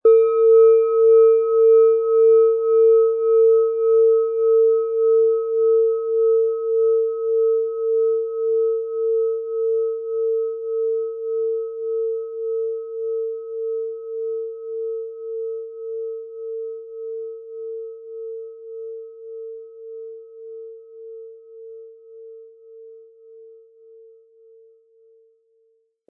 Planetenschale® Geborgen fühlen & Tief in Entspannung fallen lassen mit Thetawellen, Ø 15,9 cm, 500-600 Gramm inkl. Klöppel
Thetawelle
Sie möchten den schönen Klang dieser Schale hören? Spielen Sie bitte den Originalklang im Sound-Player - Jetzt reinhören ab.
Lieferung mit richtigem Schlägel, er lässt die Planetenschale Thetawelle harmonisch und wohltuend schwingen.
HerstellungIn Handarbeit getrieben
MaterialBronze